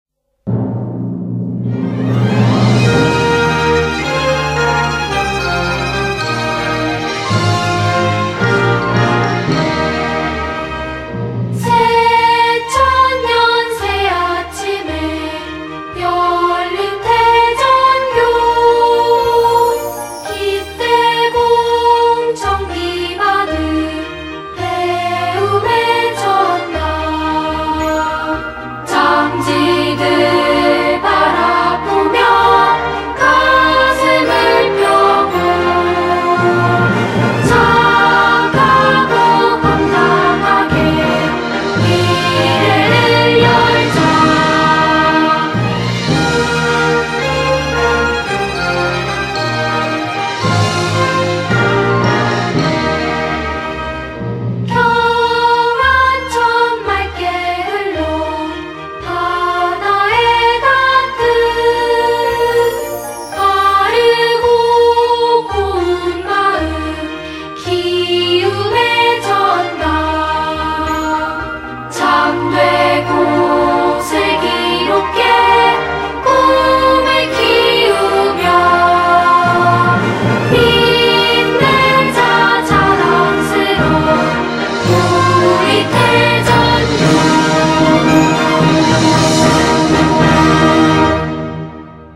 교가